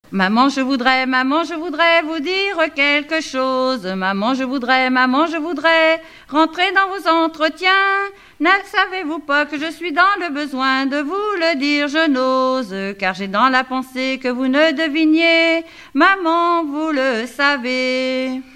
Dialogue mère-fille
Pièce musicale inédite